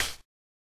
Hat
Original creative-commons licensed sounds for DJ's and music producers, recorded with high quality studio microphones.
subtle-reverb-hi-hat-sound-g-sharp-key-11-M6x.wav